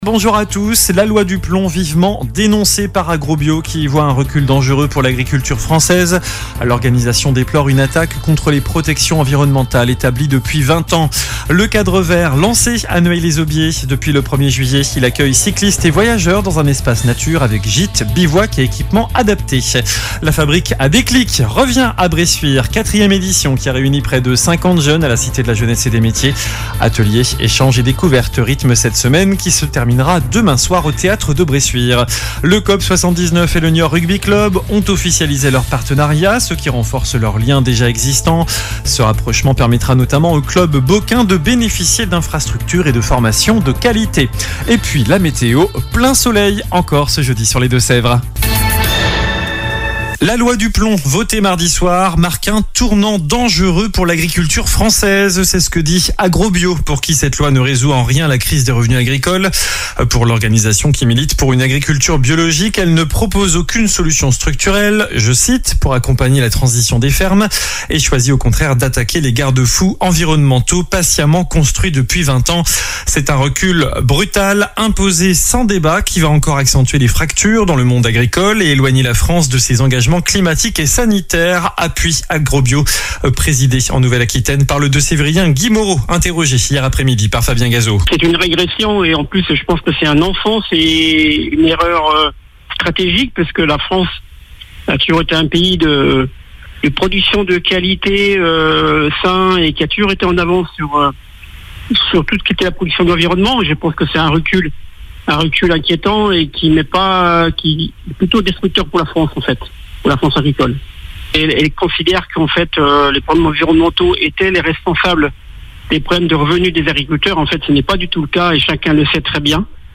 Journal du jeudi 10 juillet (midi)
infos locales